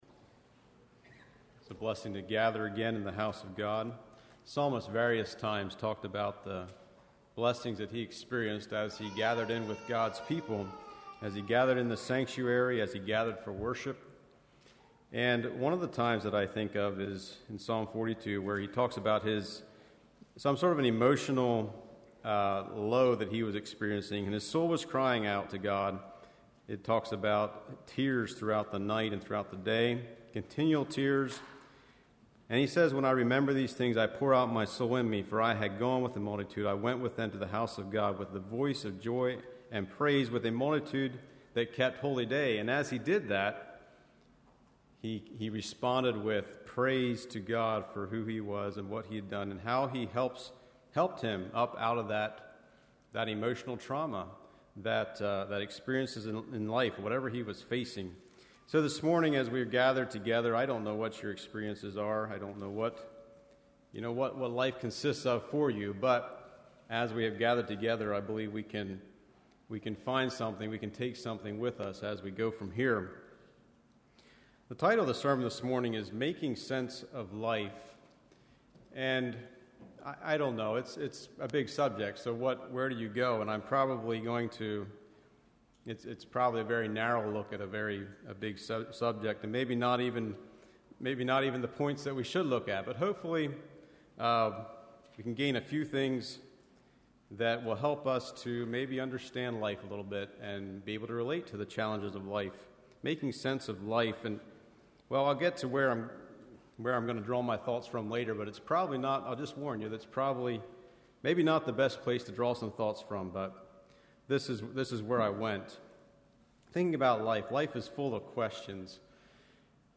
Play Now Download to Device Making Sense of Life Congregation: Swatara Speaker